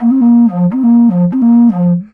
Index of /90_sSampleCDs/Best Service ProSamples vol.52 - World Instruments 2 [AIFF, EXS24, HALion, WAV] 1CD/PS-52 AIFF WORLD INSTR 2/WOODWIND AND BRASS/PS MOCENO BASSFLUTE LICKS